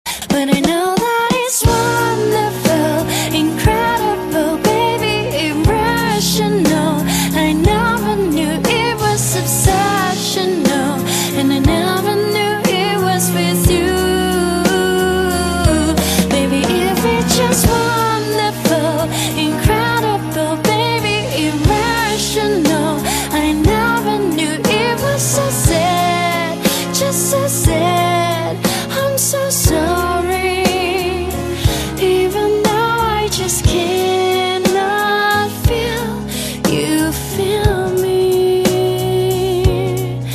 M4R铃声, MP3铃声, 欧美歌曲 41 首发日期：2018-05-14 11:09 星期一